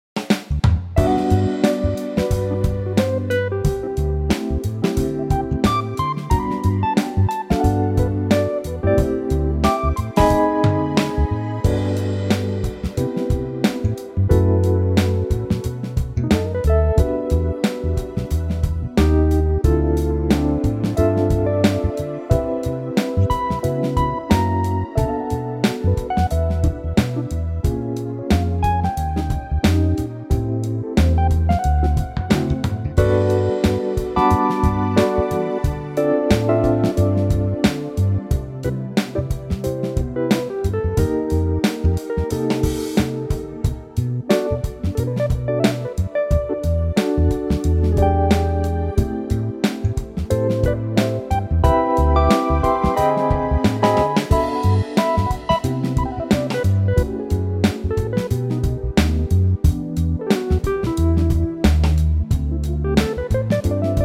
key - G - vocal range - G to A